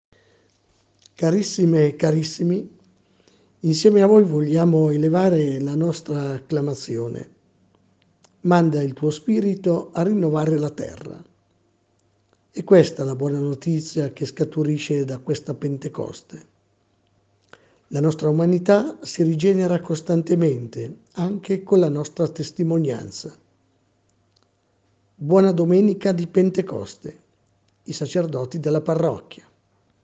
Messaggio dei sacerdoti della Parrocchia